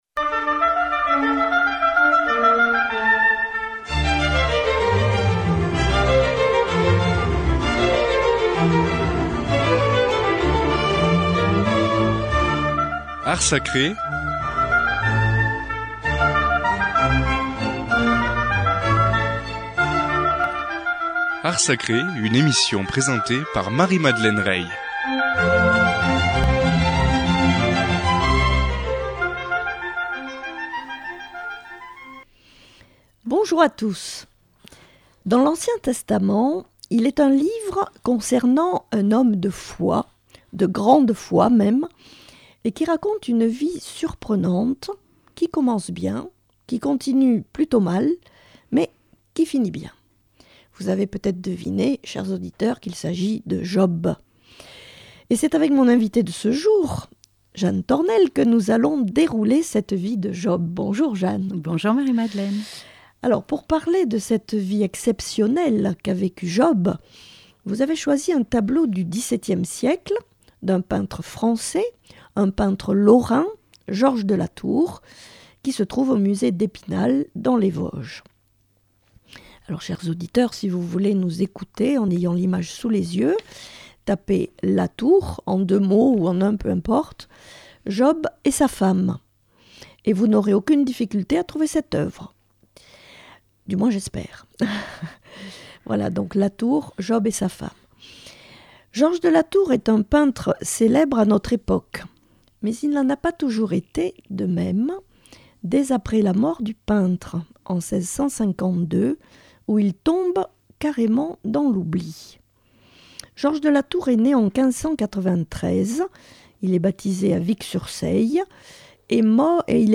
C’est avec mon invitée de ce jour que nous allons dérouler cette vie de Job.